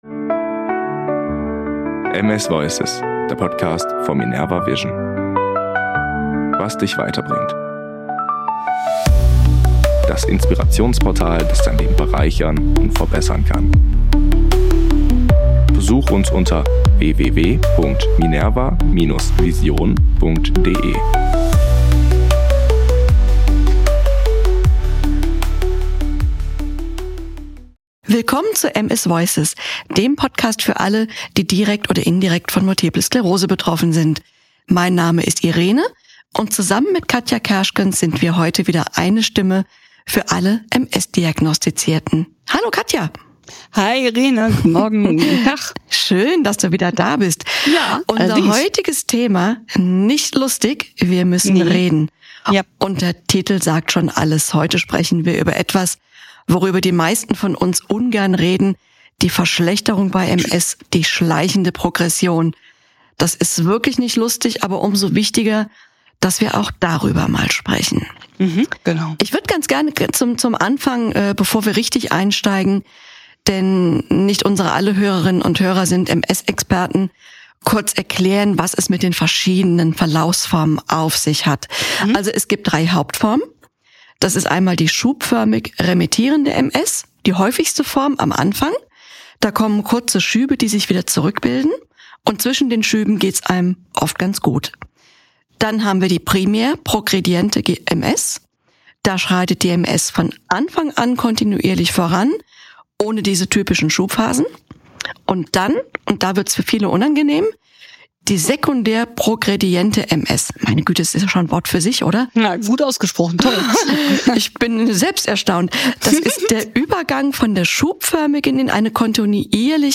Ein ehrliches, berührendes Gespräch über Unsicherheit, Hoffnung und die Kunst, stark zu bleiben.